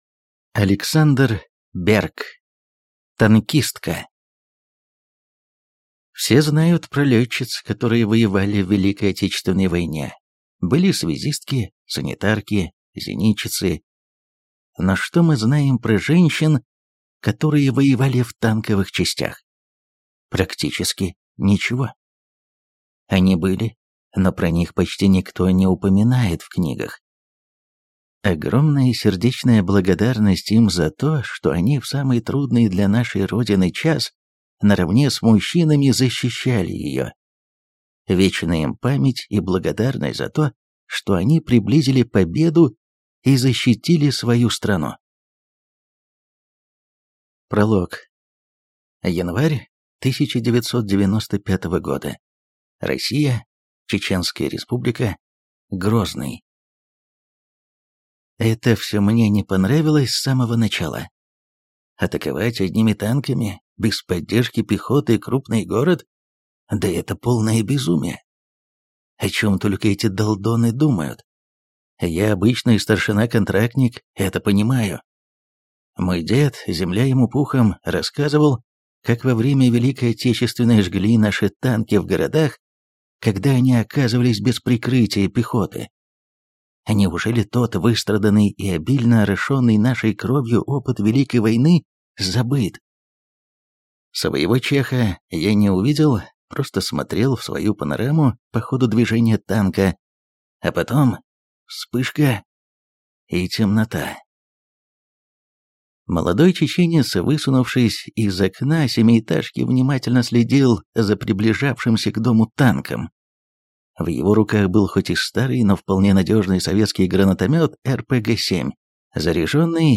Аудиокнига Танкистка | Библиотека аудиокниг